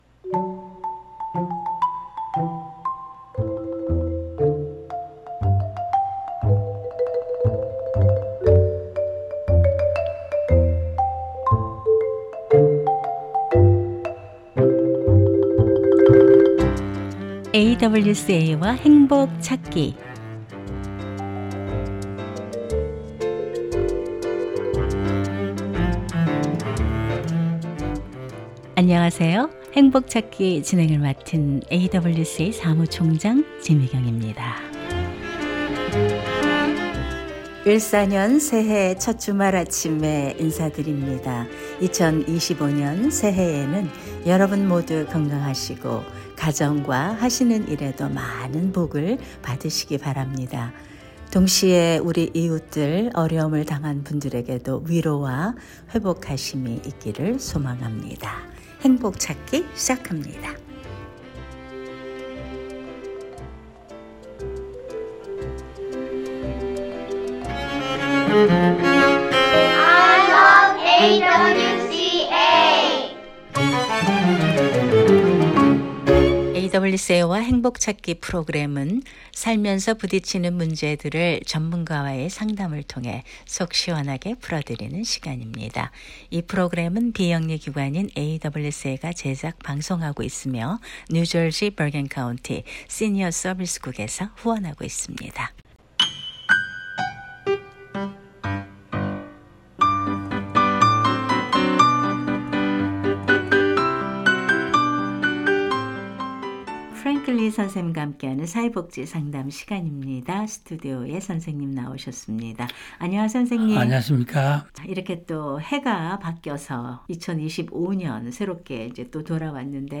신년 특별 인터뷰